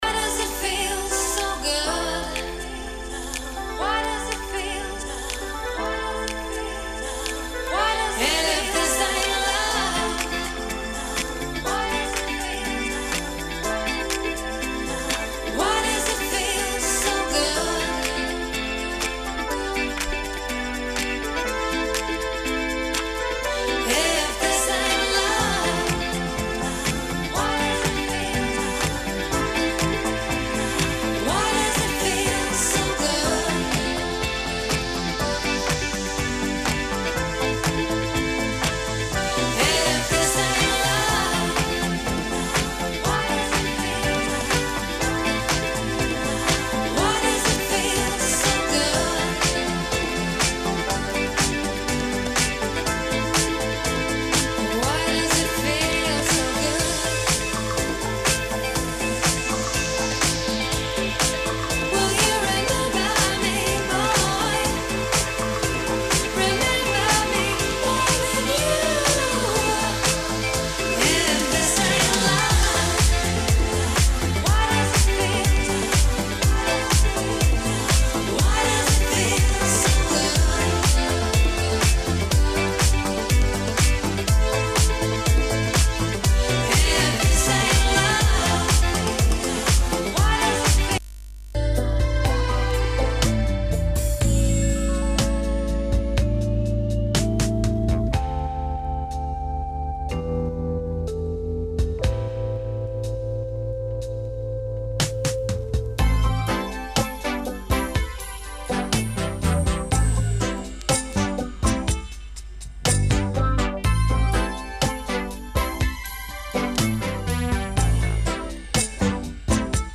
House-Trance